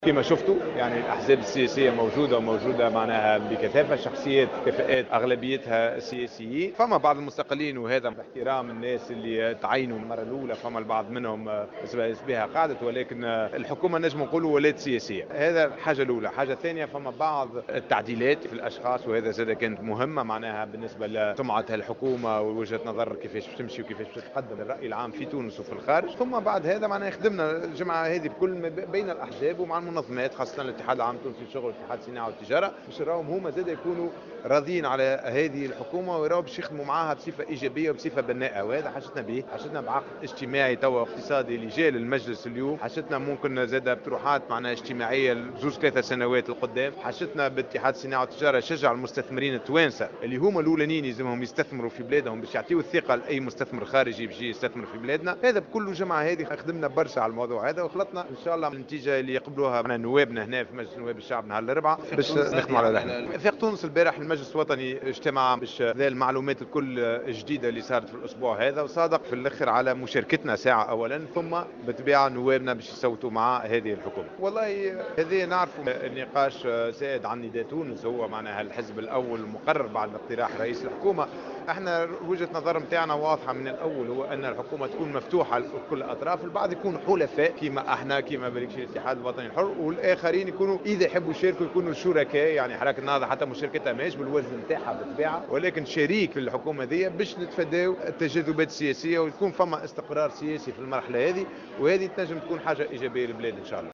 وأضاف ياسين ابراهيم في تصريح لمراسل جوهرة أف أم اليوم الاثنين أن المجلس الوطني لحزبه صادق خلال اجتماعه أمس على مشاركته في الحكومة الجديدة التي أعلن عنها الحبيب الصيد صباح اليوم .